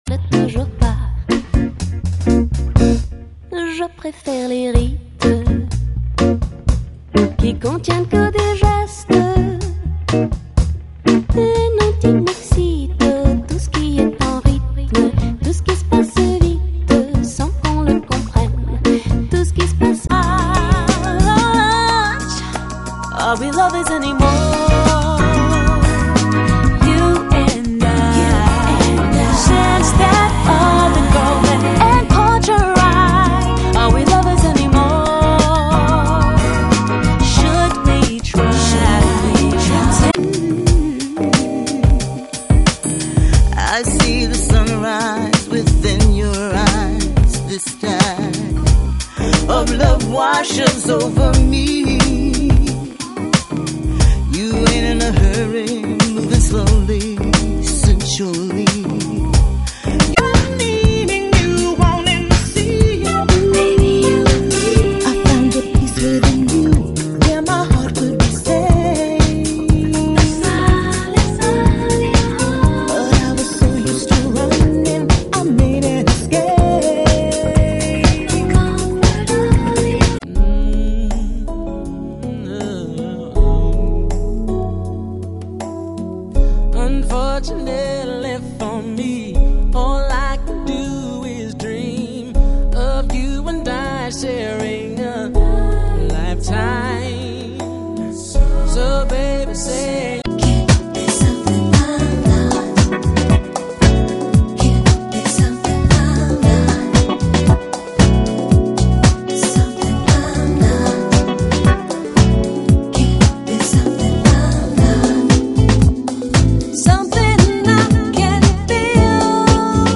Soulish. Acoustic.
Too many gadgets and "fun" samples.
MP3 sound bite (3.7MB). 20 seconds from each song.